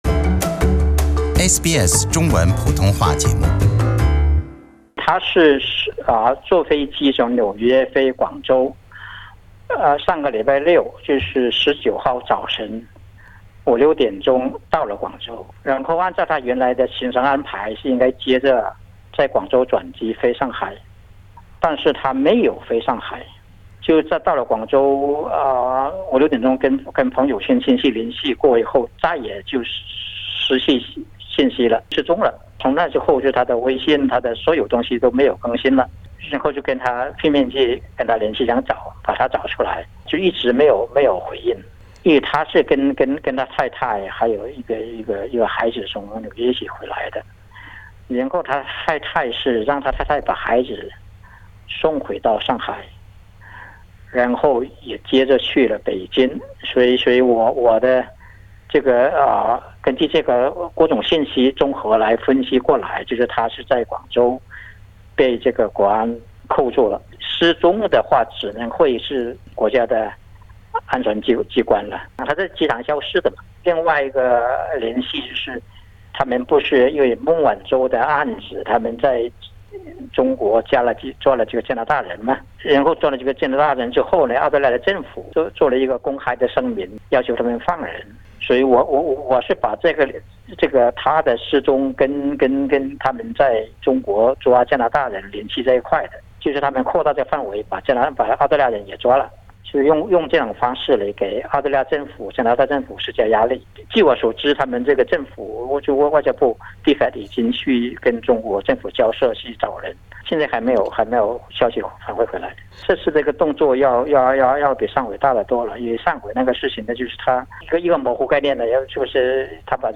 （采访内容仅代表嘉宾观点，不代表本台立场） 关注更多澳洲新闻，请在Facebook上关注SBS Mandarin，或在微博上关注澳大利亚SBS广播公司。